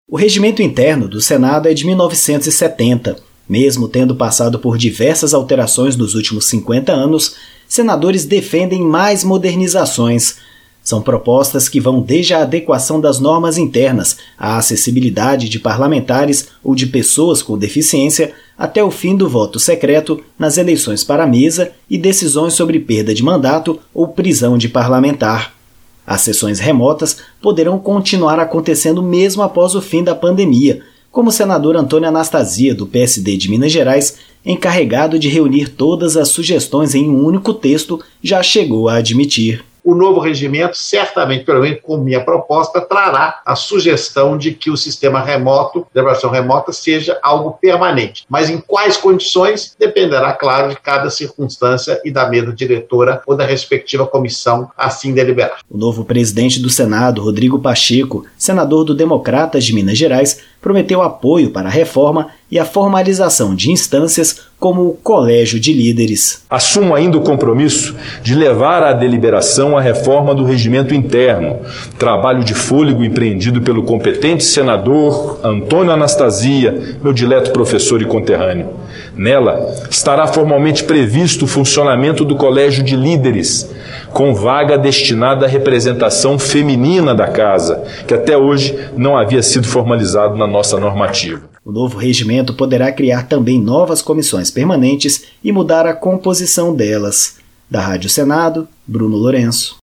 A reportagem